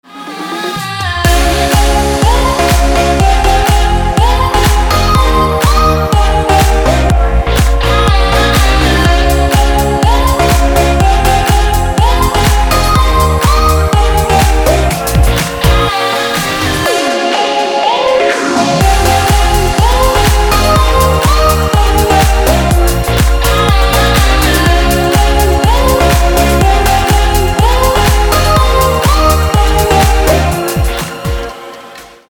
• Качество: 256, Stereo
dance
Electronic
club
electro